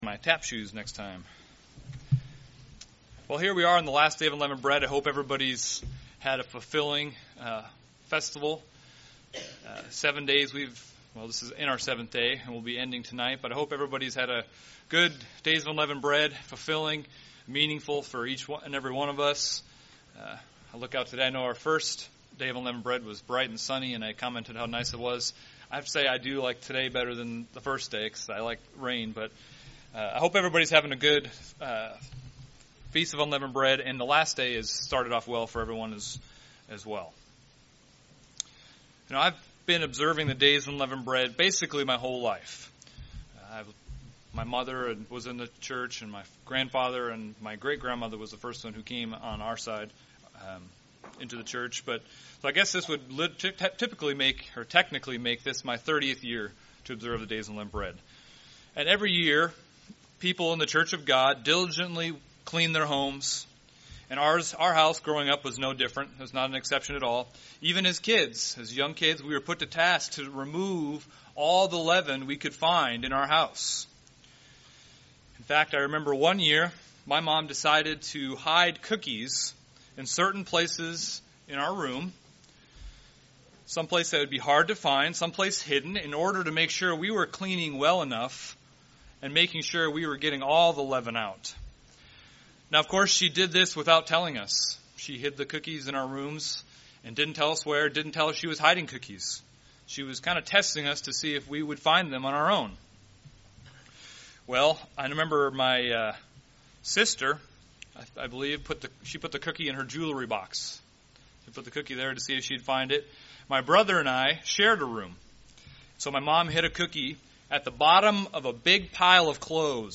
During the Days of Unleavened Bread, we learn the lessons of removing sin from our lives. In this sermon, the topic of purging our sins is explored.